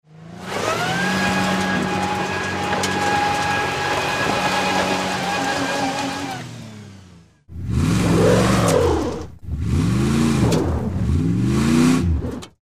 zvuki-probuksovki_006
zvuki-probuksovki_006.mp3